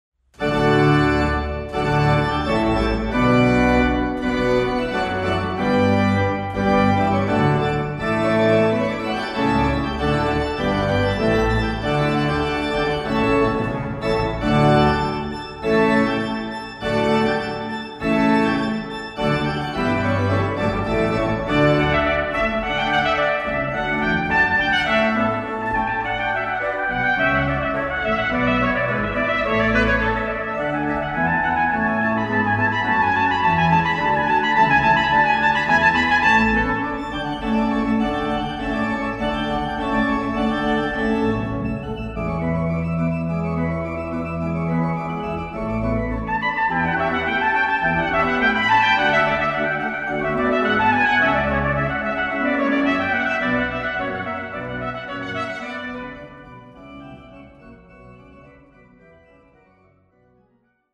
Gattung: für Trompete und Orgel